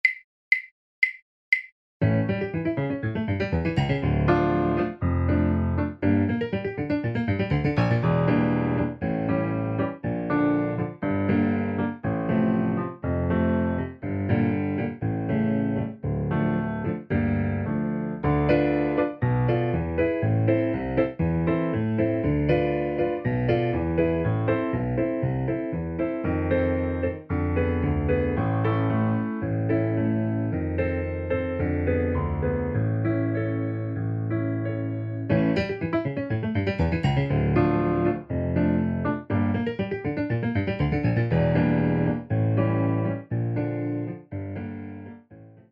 SAMPLE BACKING TRACK